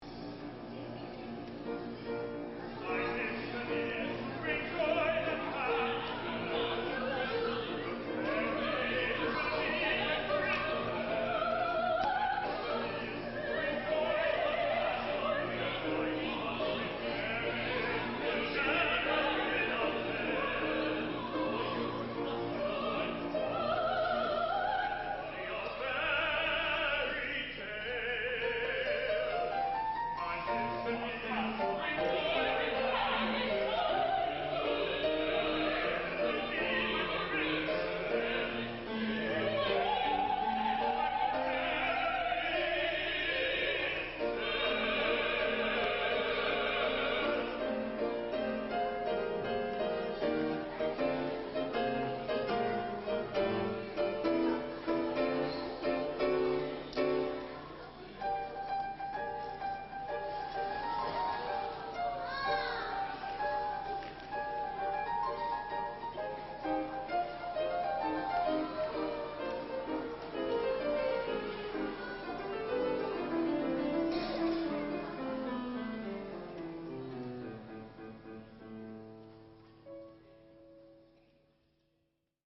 a one-act opera for young audiences